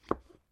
Звуки грибов
Звук разрезания гриба пополам